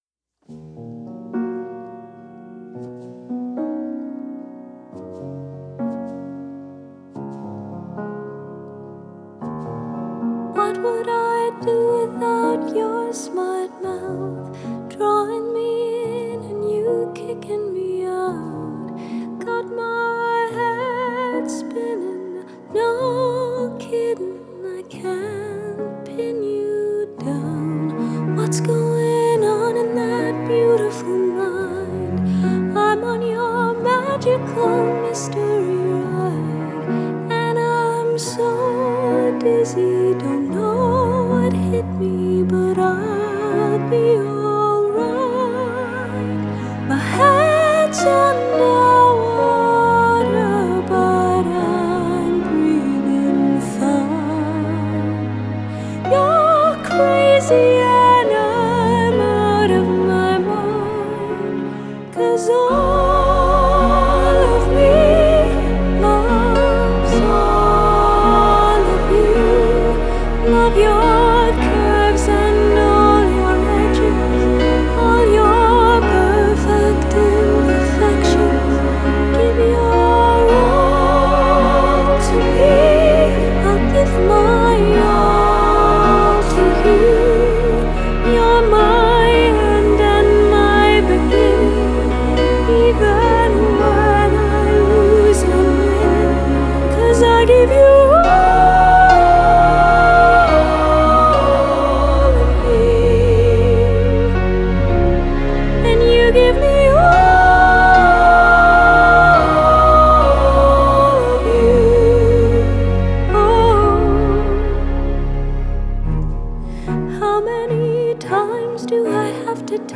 Genre: Classical Crossover, Classical